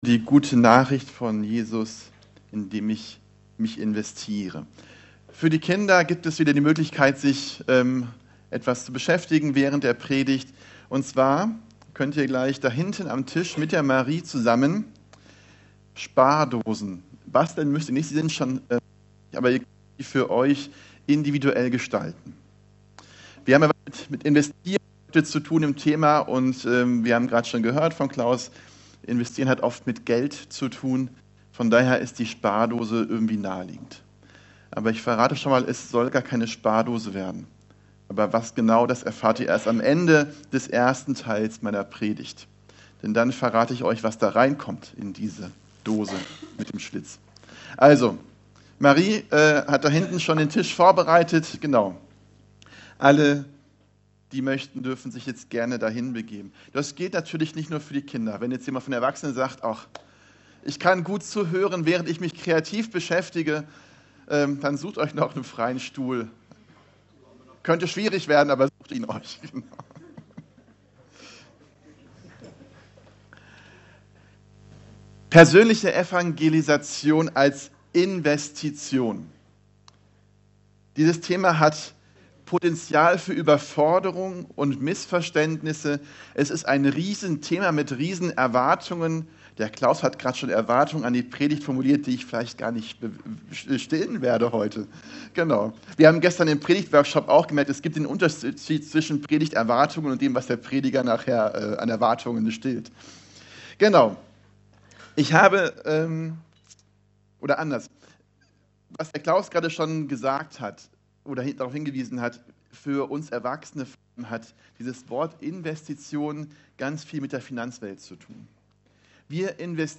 Evangelisch-Freikirchliche Gemeinde Kelkheim - Predigten anhören